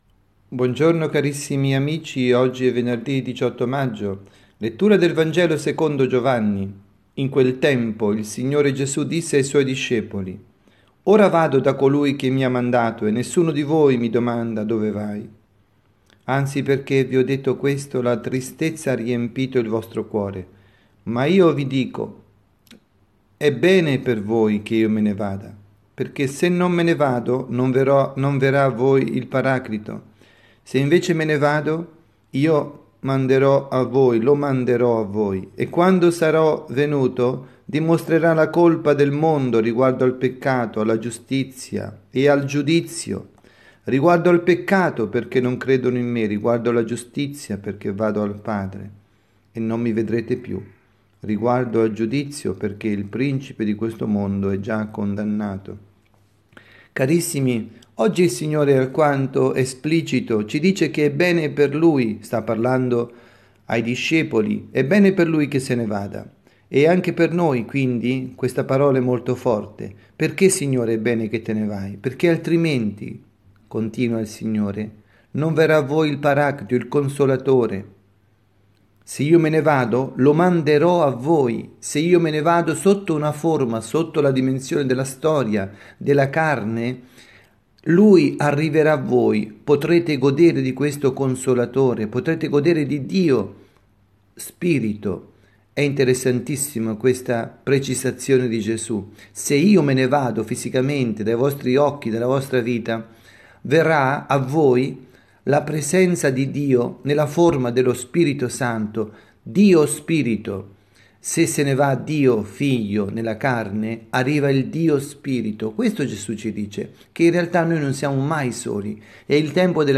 Omelia
dalla Casa di Riposo Santa Marta – Milano